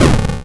ihob/Assets/Extensions/explosionsoundslite/sounds/bakuhatu11.wav at master
bakuhatu11.wav